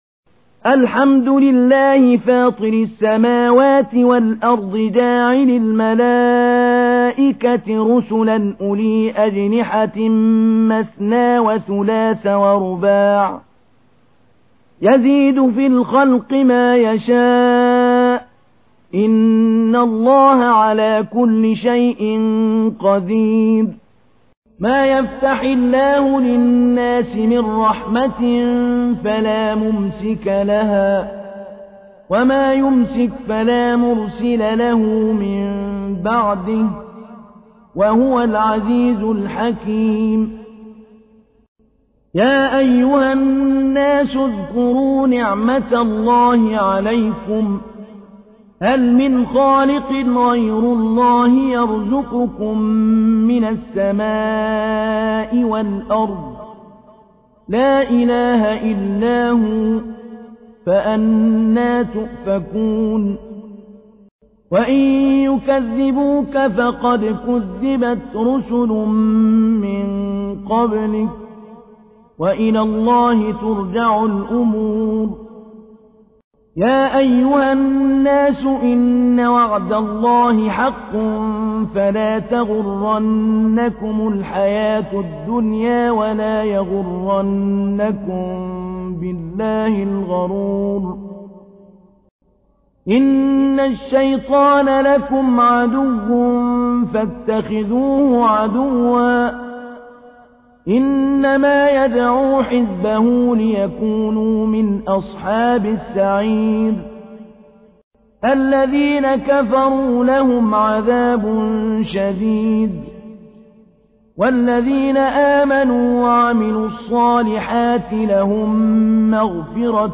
تحميل : 35. سورة فاطر / القارئ محمود علي البنا / القرآن الكريم / موقع يا حسين